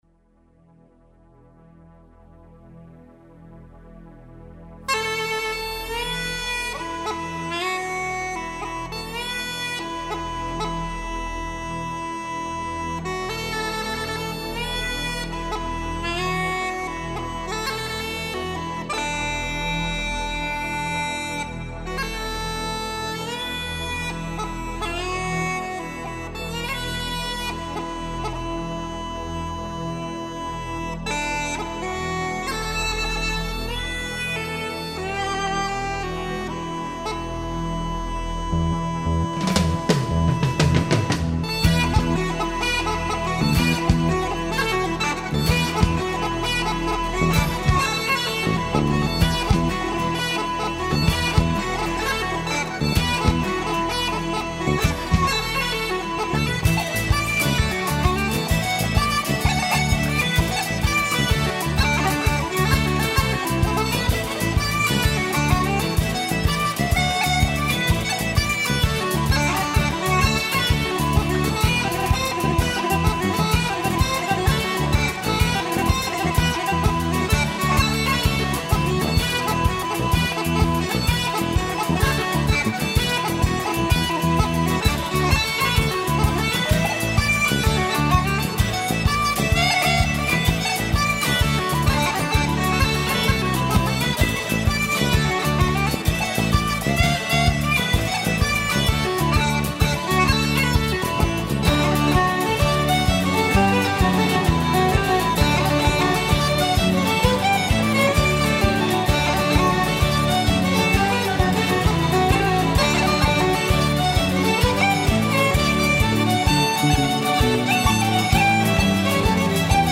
Fiddle
Drums
Uillean pipes whistle guitar vocals
Bass
Keyboards